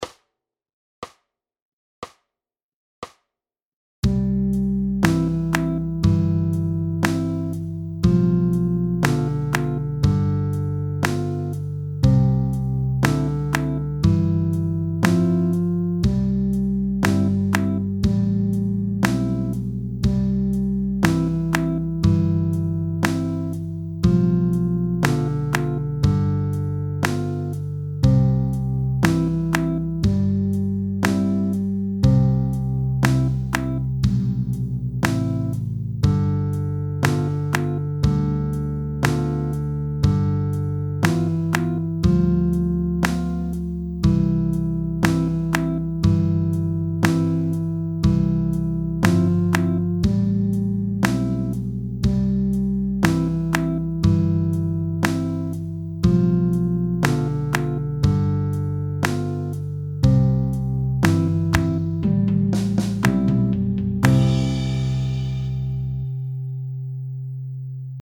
Mit Gesang